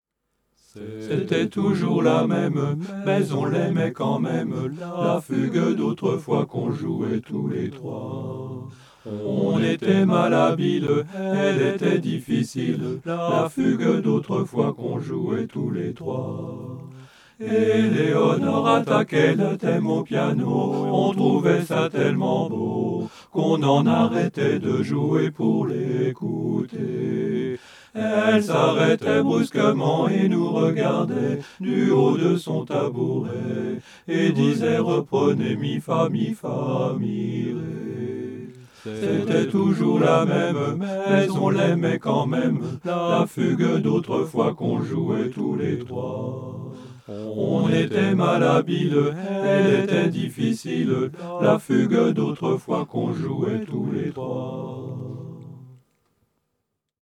A 4 vx